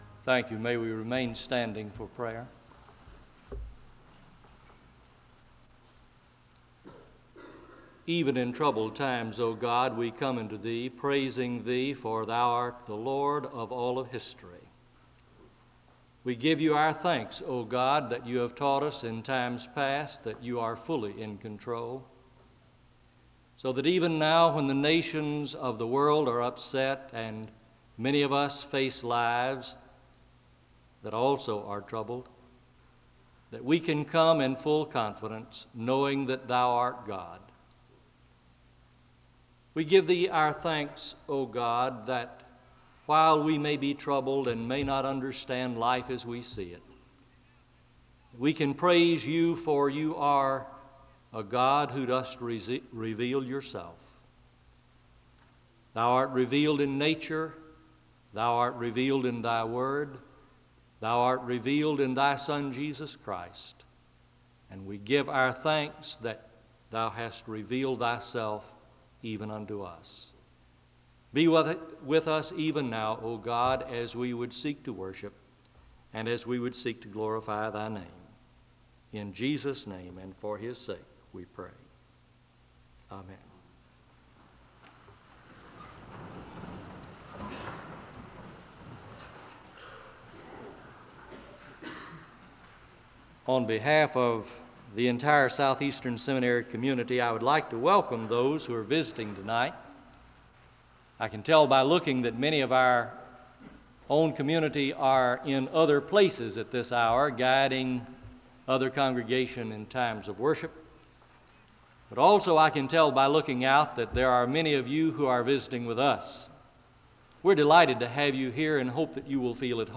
The conference opens with a word of prayer (00:00-01:24). The speaker gives a brief introduction to the topic of discussion, and he has the audience read from Article 7 of the Abstract of Principles (01:25-06:07).
The choir leads in a song of worship (10:04-13:30).